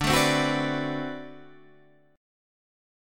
D13 chord